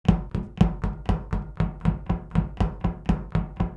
Бег по металлу nПробежка по железу nЖелезный бег